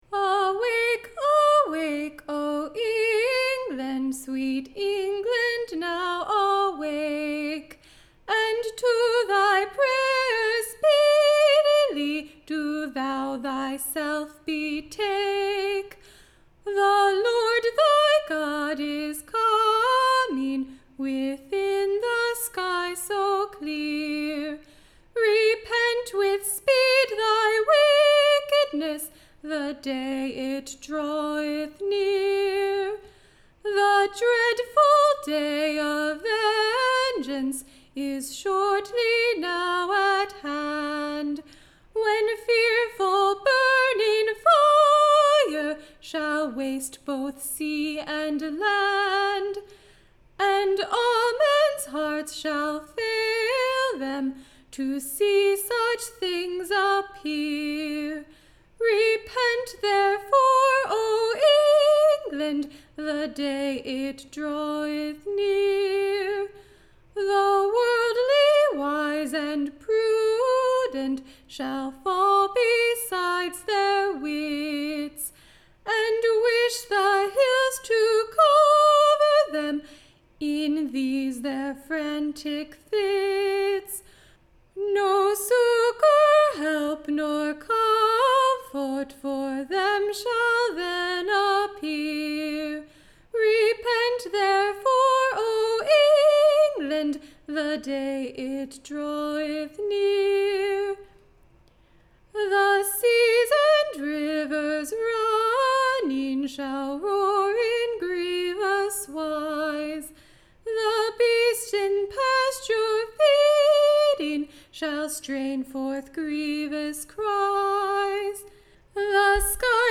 Recording Information Ballad Title A new Ballad intituled, A Bell-man for England, which night and day doth sta. / ring in all mens hearing, Gods vengeance is at hand. Tune Imprint To the tune of, O man in desperation.